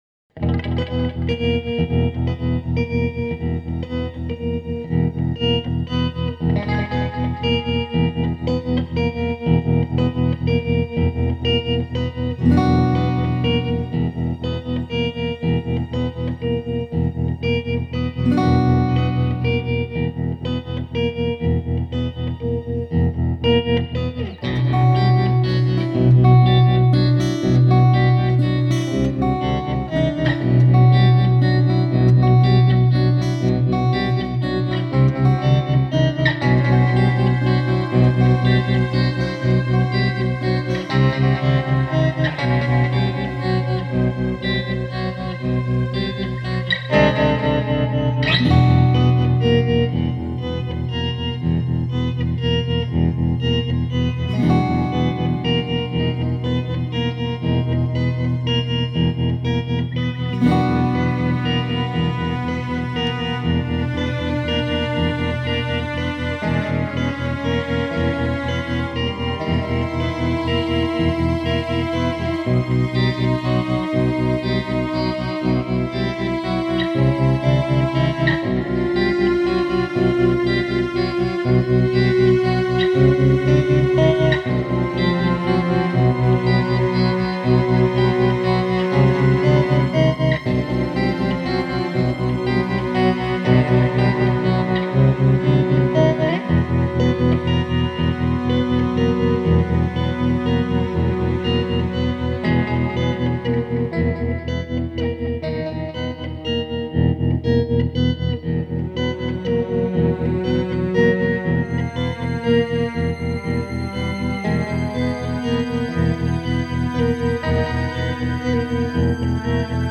playing a repeating motif on electric guitar with a lot of reverb and echo, while I’m on acoustic guitar doing some arpeggiated figures. It’s mostly in minor, but it has this nice moment at 1:12, where it resolves to major in a very obvious way.